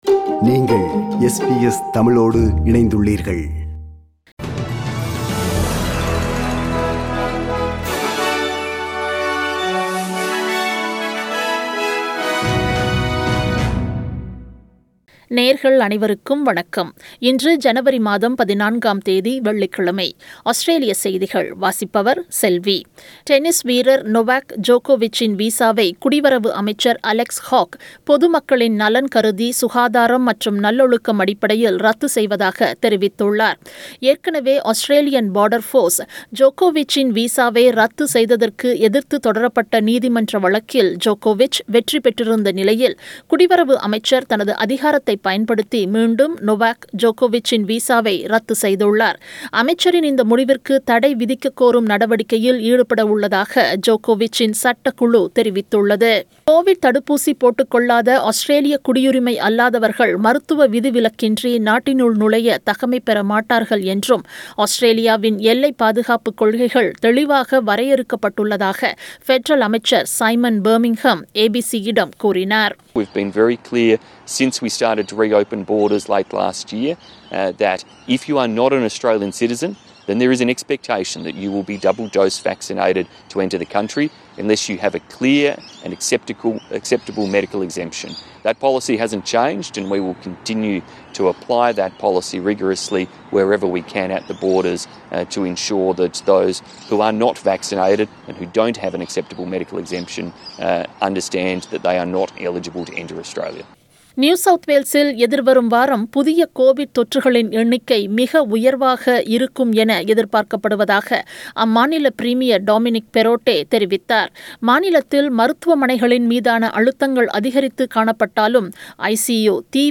Australian news bulletin for Friday 14 January 2022.